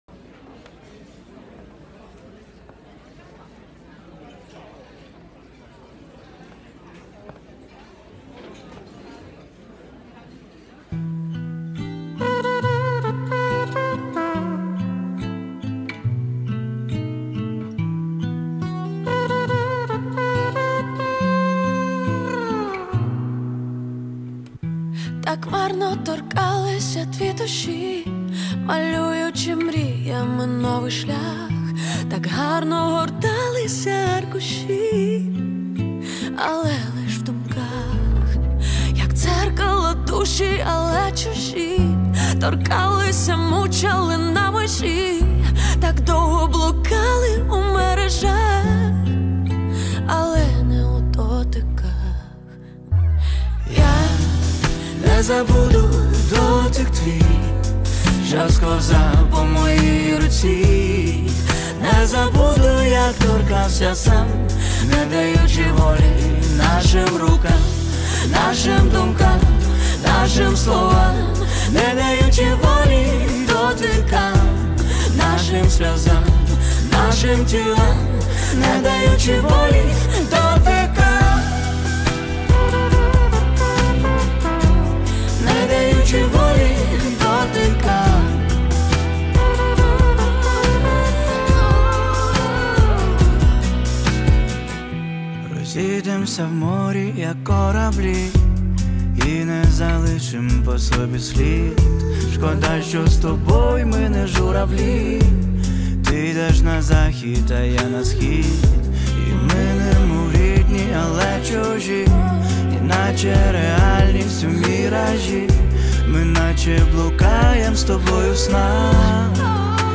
Всі мінусовки жанру Pop-UA
Плюсовий запис